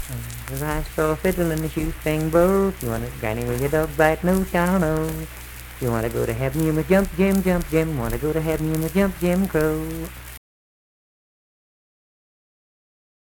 Unaccompanied vocal music performance
Minstrel, Blackface, and African-American Songs
Voice (sung)
Braxton County (W. Va.)